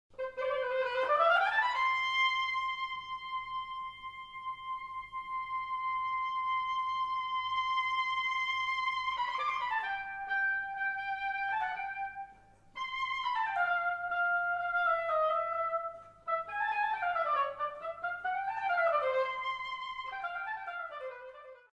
VIENTO MADERA
OBOE